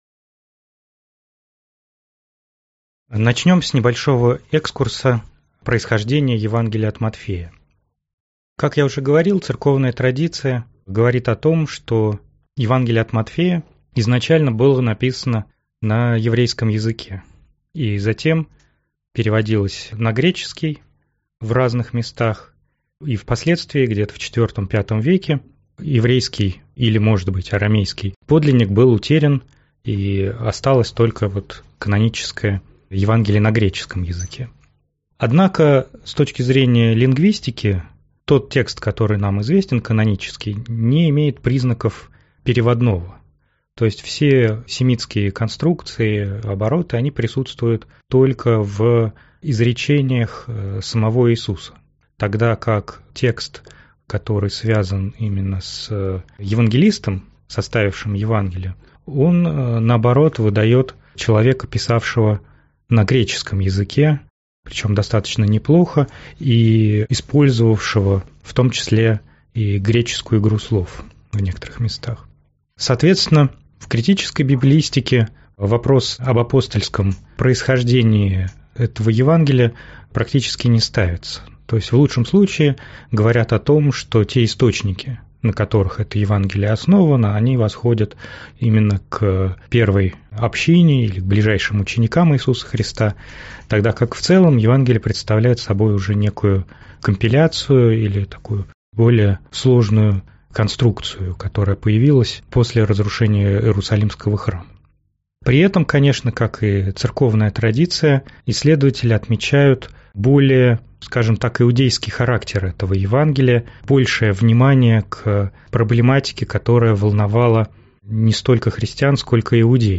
Аудиокнига Евангелие от Матфея. Притчи Иисуса.